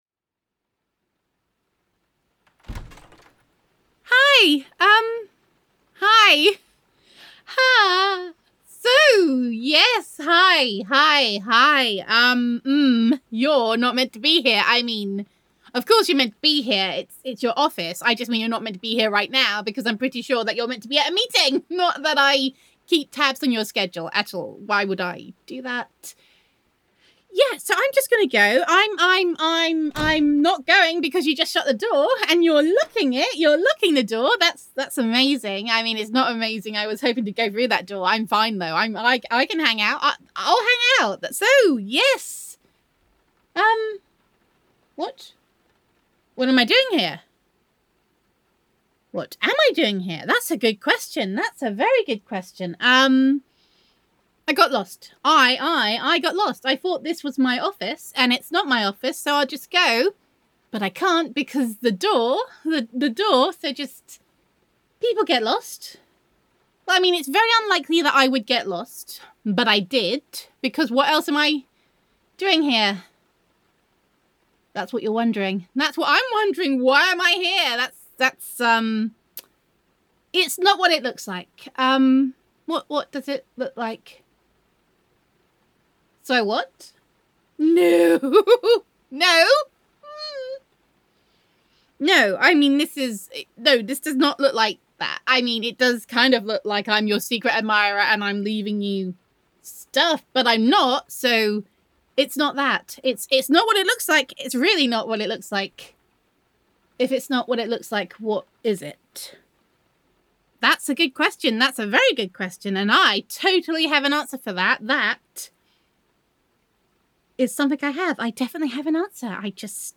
[F4A] Caught Red-handed and Red-faced [Co-Worker Roleplay]